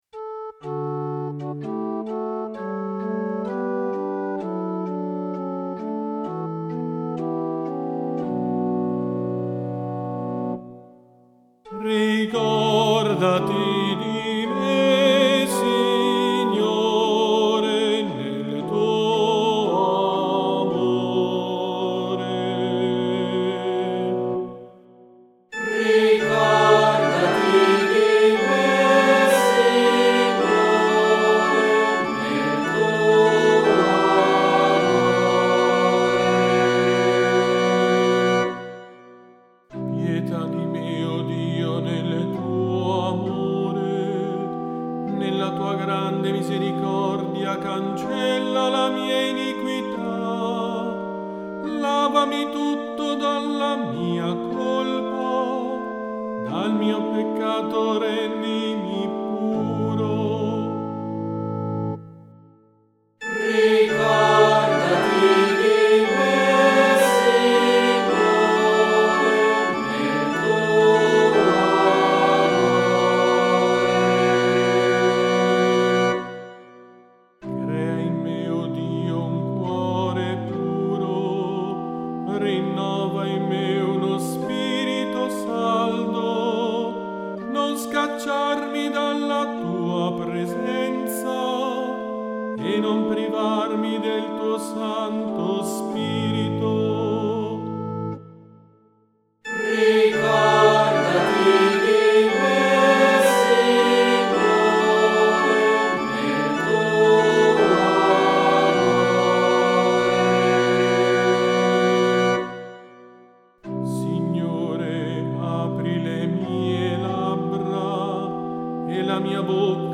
Salmo responsoriale
salmo-toC24.mp3